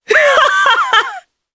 Morrah-Vox_Happy3_jp.wav